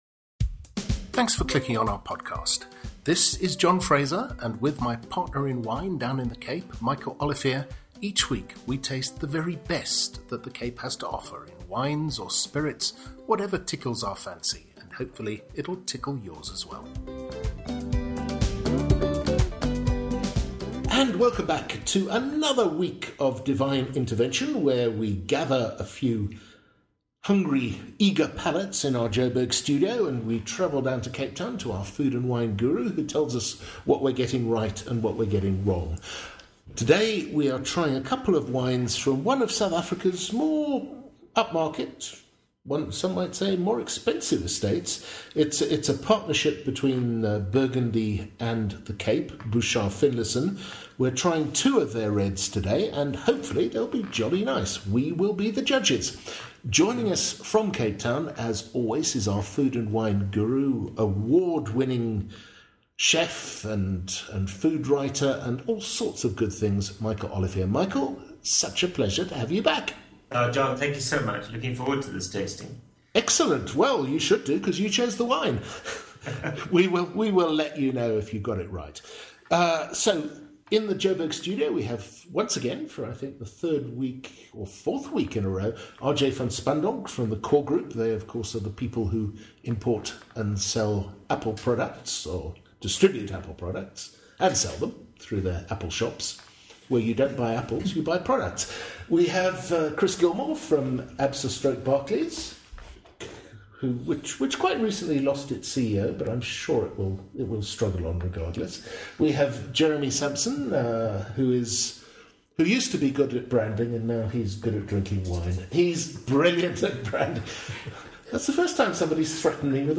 The panel also discusses the merits of cellaring wine over time.